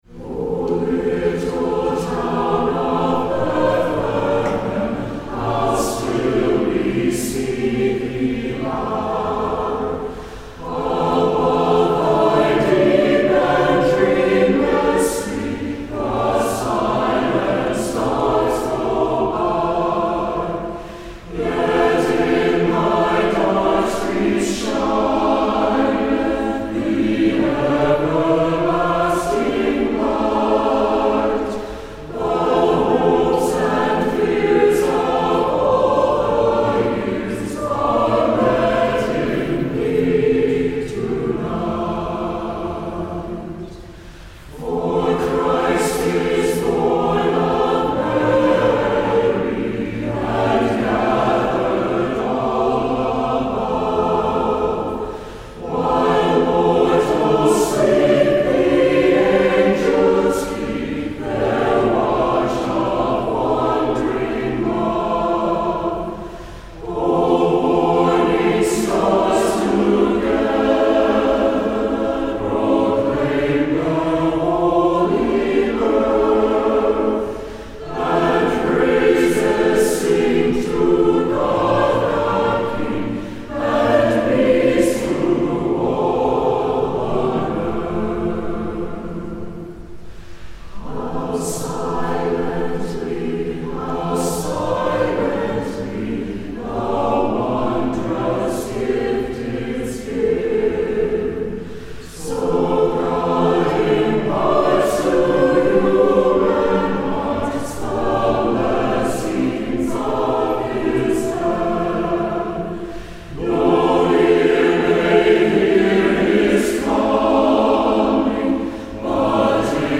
Musical Rendition & Lyrics
Music provided by members of the First Presbyterian Church Sanctuary Choir